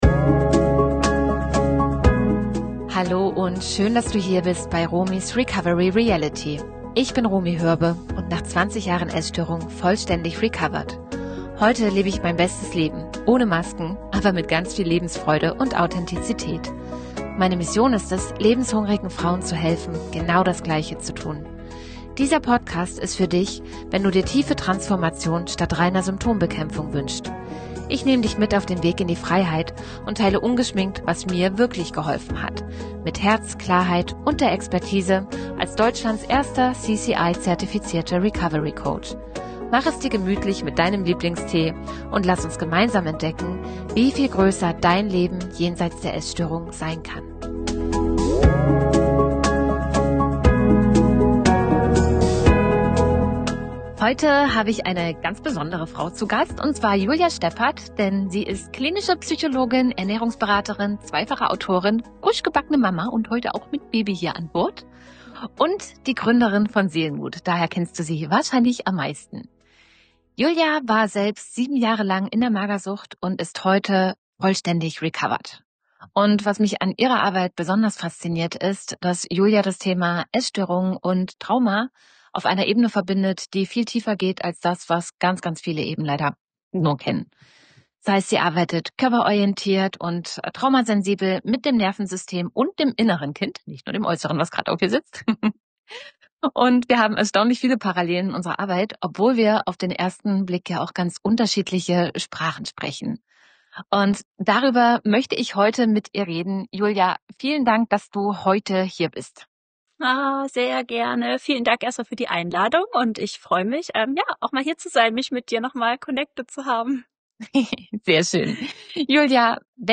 #49 Entwicklungstrauma & Recovery – Interview